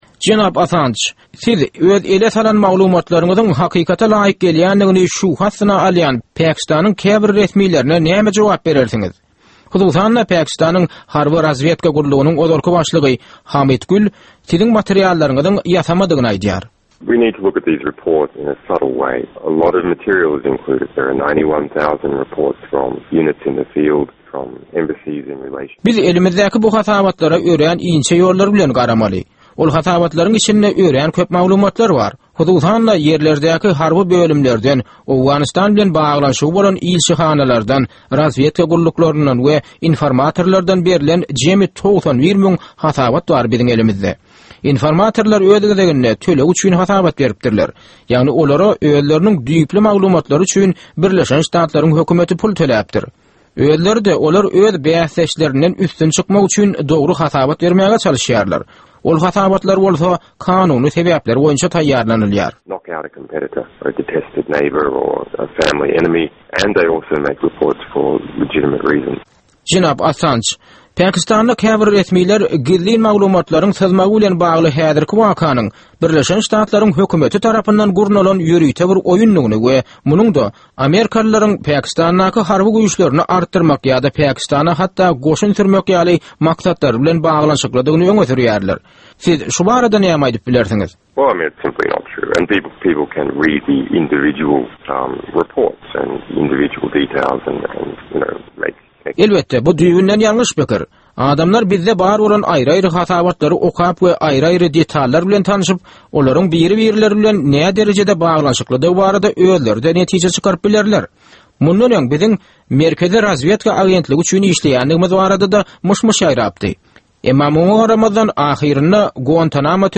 «WikiLeaks» saýtynyň esaslandyryjysy bilen söhbet